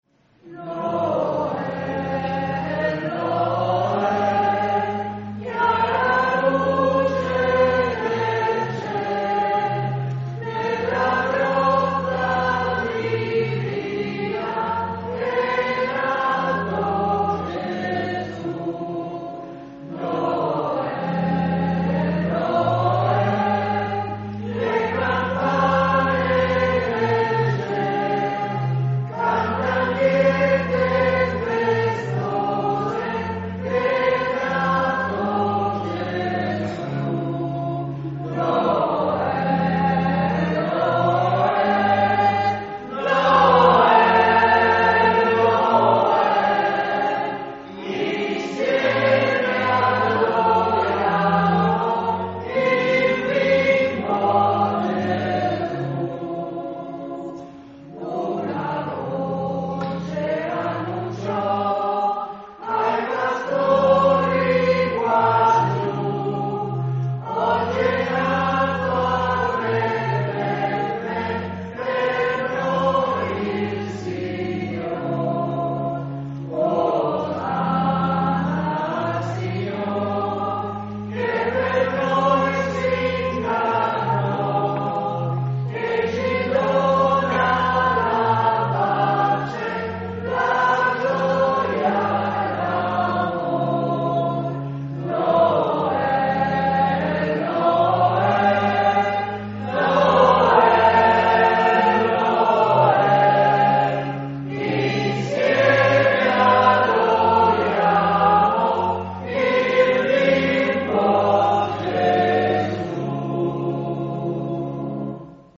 Istituto Gervasutta di Udine (UD), 22 Dicembre 2024
Santa Messa dell'Ammalato
Accompagnata dalla "Corale Gioconda"
CANTO ALL'OFFERTORIO
La Corale Gioconda è un coro costituito da persone affette dalla malattia di Parkinson e da alcuni dei loro familiari.